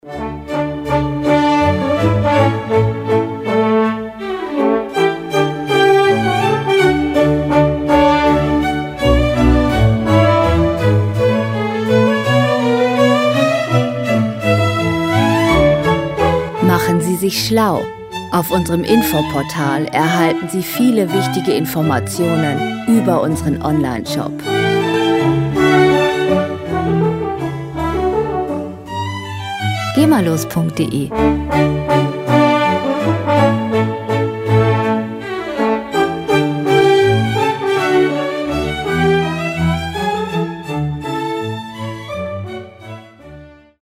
in einer glanzvollen, sehr raschen und lebendigen Variante
• Wiener Klassik